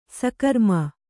♪ sakarma